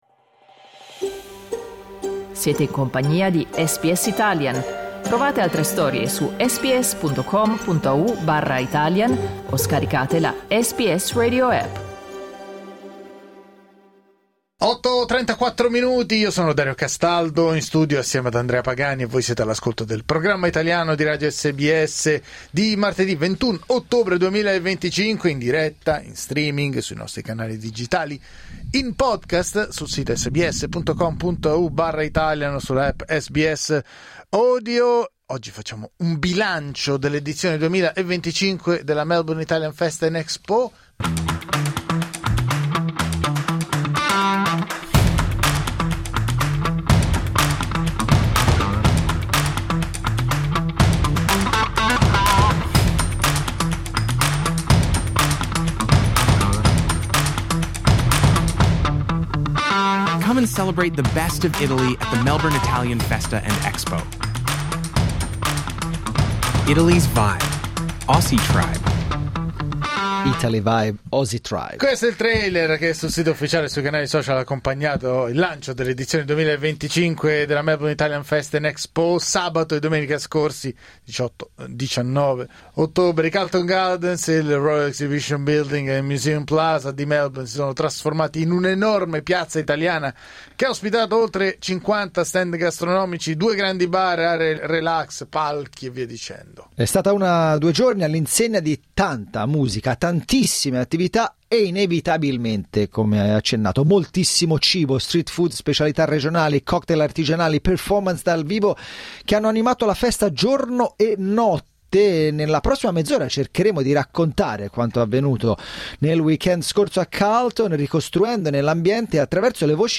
Abbiamo chiesto a visitatori, espositori e organizzatori di tracciare un bilancio dell'appuntamento classico della comunità italiana del Victoria, che nello scorso fine settimana ha richiamato oltre 100mila persone al Royal Exhibition building.